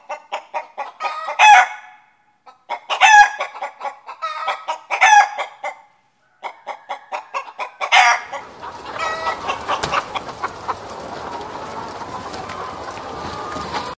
دانلود آهنگ مرغ 1 از افکت صوتی انسان و موجودات زنده
دانلود صدای مرغ 1 از ساعد نیوز با لینک مستقیم و کیفیت بالا
جلوه های صوتی